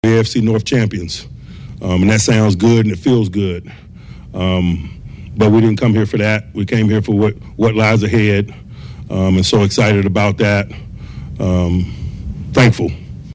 Tomlin says the win last night was just one step toward the Steelers’ ultimate goal.
nws0581-mike-t-here-we-go.mp3